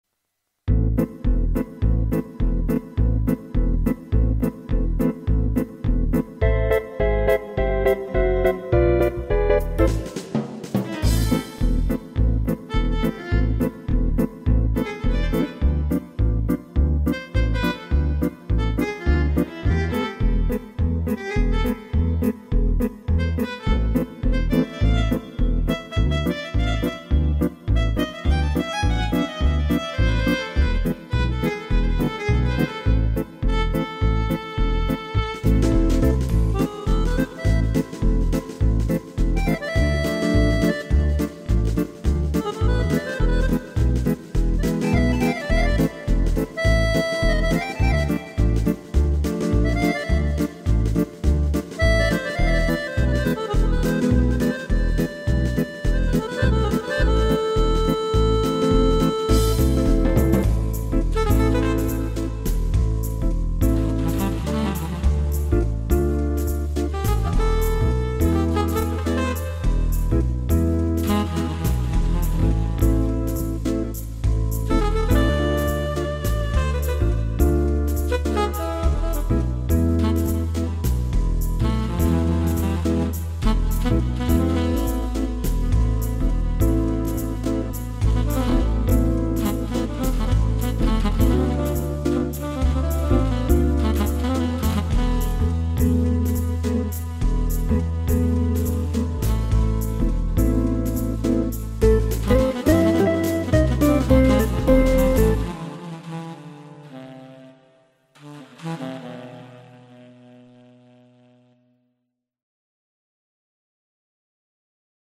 标签： 即兴 爵士 性质 小号
声道立体声